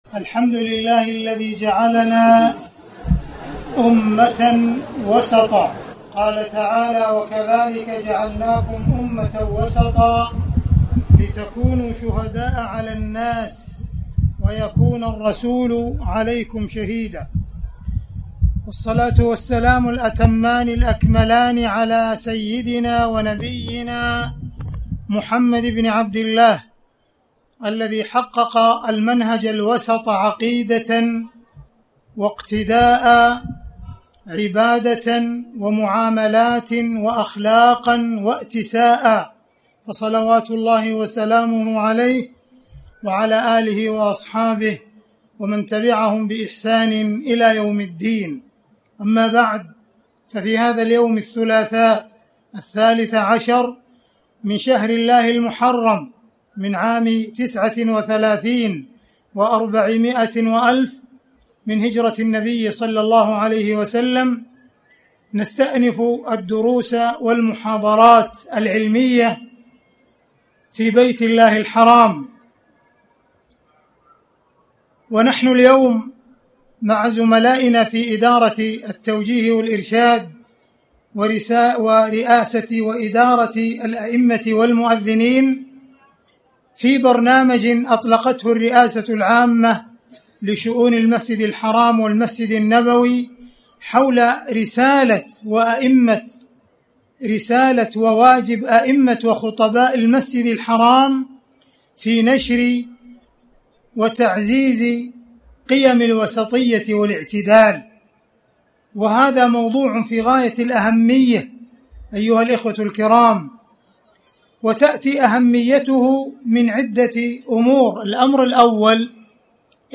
تاريخ النشر ١٣ محرم ١٤٣٩ المكان: المسجد الحرام الشيخ: معالي الشيخ أ.د. عبدالرحمن بن عبدالعزيز السديس معالي الشيخ أ.د. عبدالرحمن بن عبدالعزيز السديس الوسطية والاعتدال